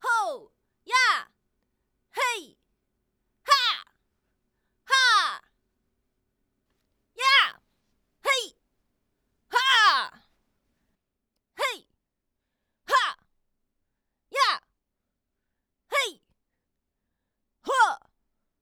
女激励1.wav
女激励1.wav 0:00.00 0:18.64 女激励1.wav WAV · 1.6 MB · 單聲道 (1ch) 下载文件 本站所有音效均采用 CC0 授权 ，可免费用于商业与个人项目，无需署名。
人声采集素材/女激励/女激励1.wav